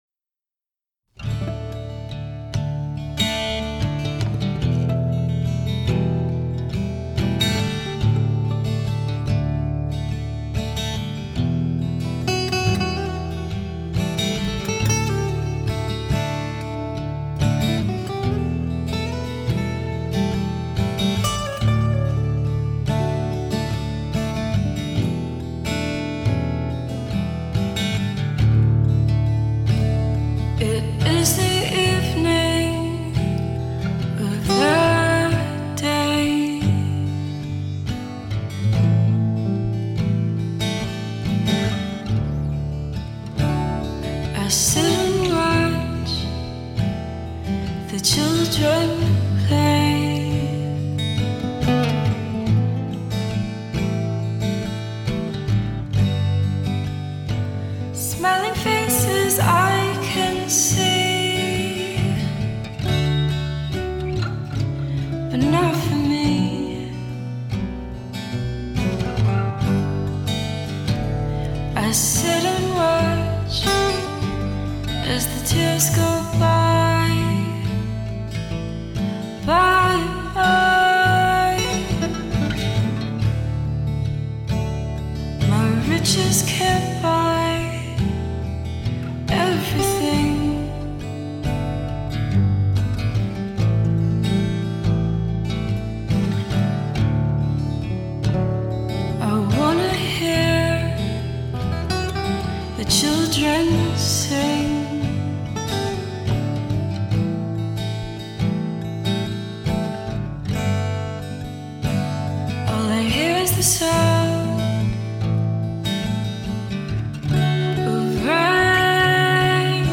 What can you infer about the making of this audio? "Reading is Funktamental" is a monthly one-hour show about great books written about music and music-makers. Expect lively conversation and a playlist of great music to go with it.